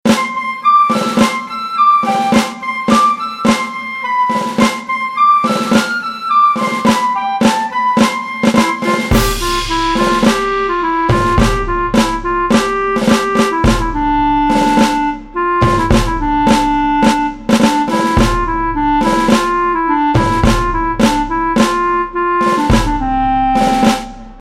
mp3 Instrumental Song Track